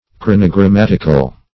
Search Result for " chronogrammatical" : The Collaborative International Dictionary of English v.0.48: Chronogrammatic \Chron`o*gram*mat"ic\, Chronogrammatical \Chron`o*gram*mat"ic*al\, a. [Cf. F. chronogrammatique.] Belonging to a chronogram, or containing one.